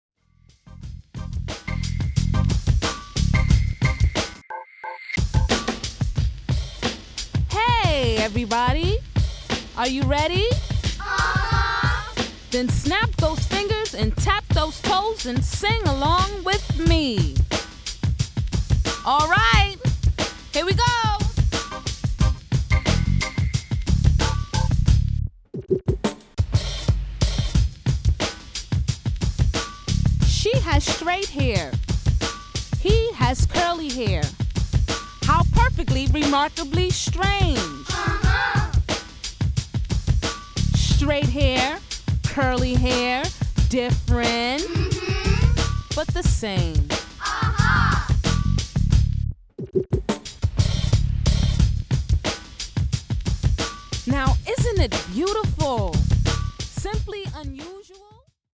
Home  >  Readalongs